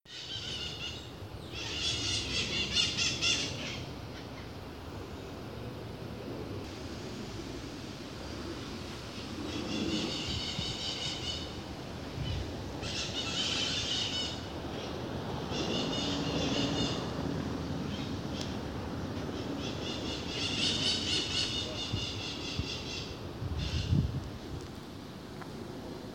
White-eyed Parakeet (Psittacara leucophthalmus)
Life Stage: Adult
Location or protected area: Gran Buenos Aires Norte
Condition: Wild
Certainty: Observed, Recorded vocal
Calancate-Ala-Roja-Call-EDIT.mp3